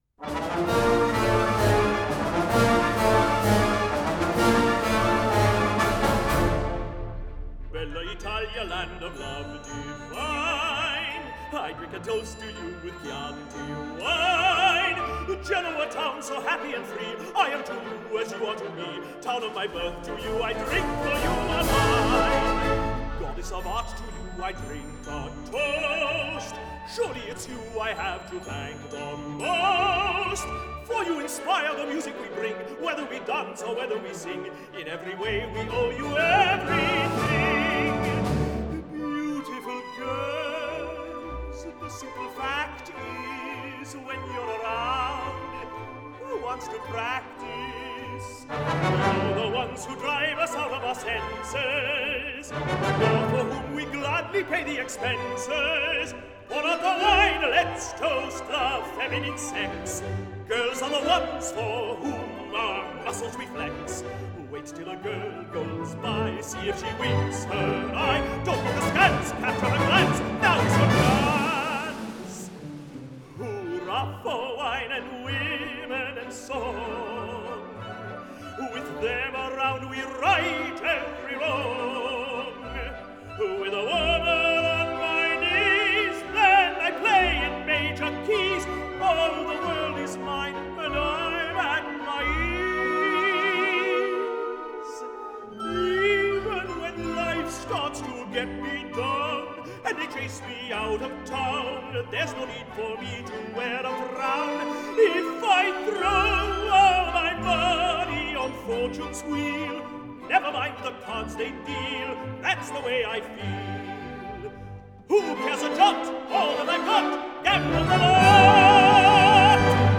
音乐类别： 轻歌剧
soprano
tenor